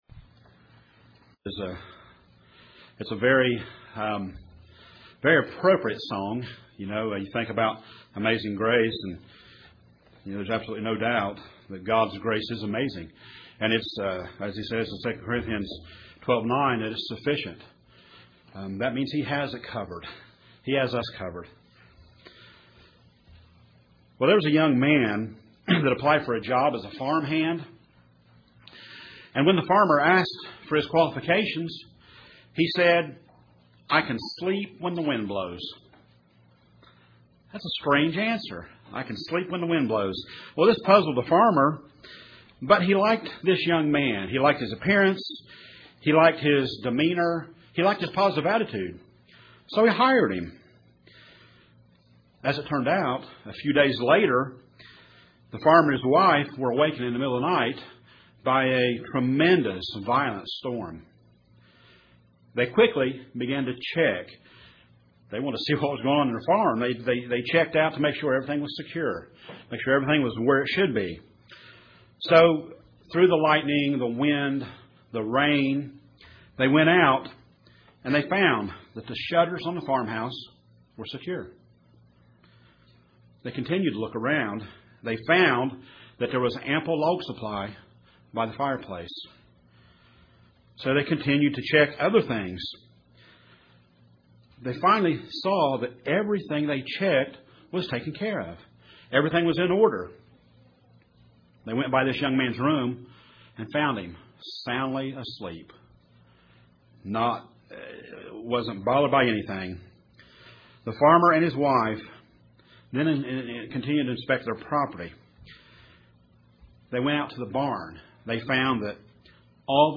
These questions and more are answered in this sermon .